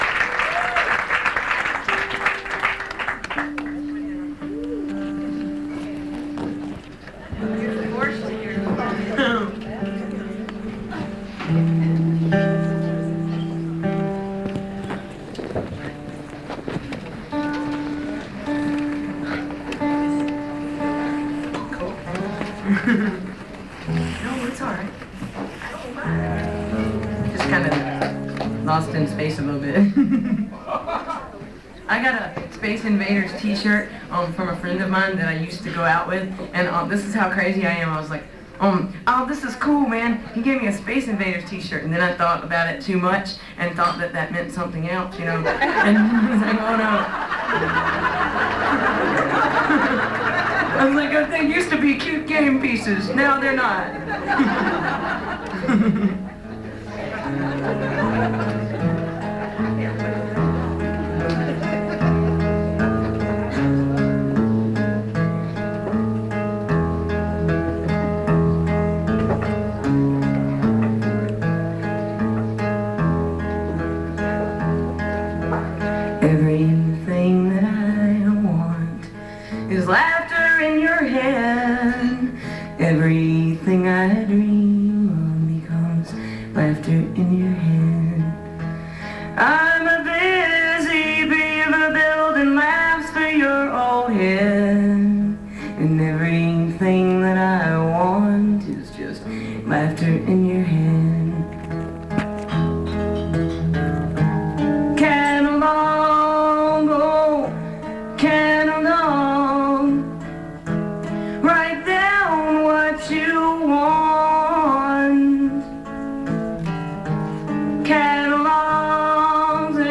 (songwriters in the round)